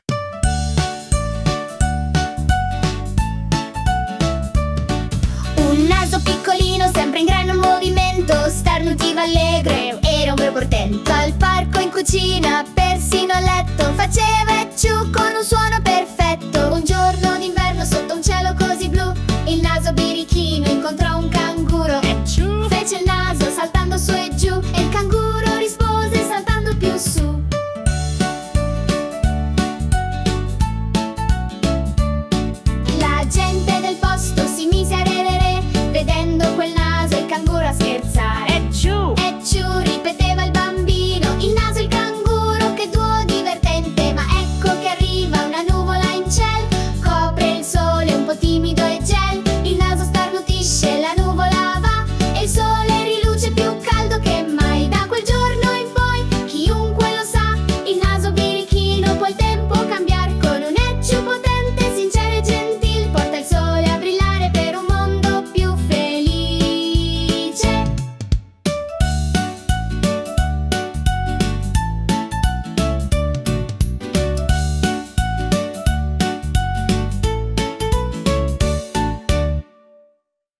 🎶 Filastrocche